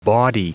Prononciation du mot : bawdy
bawdy.wav